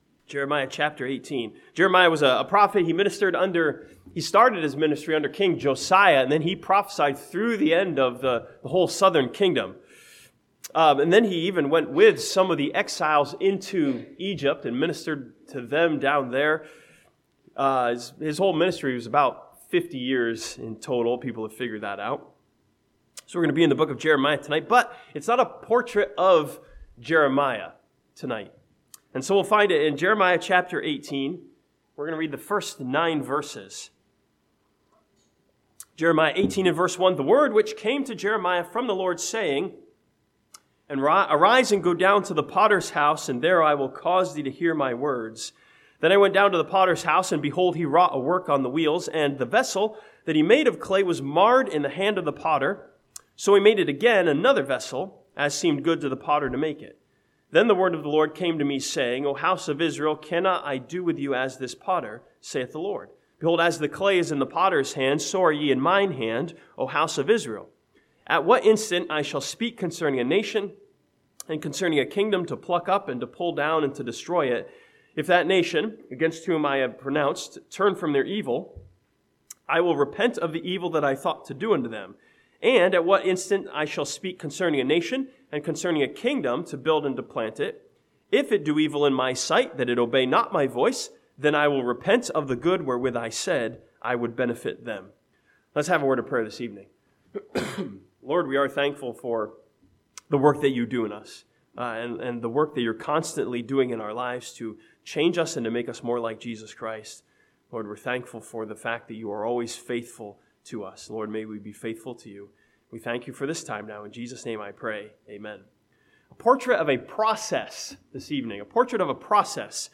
This sermon from Jeremiah chapter 18 studies the story of a potter and the process of molding a vessel.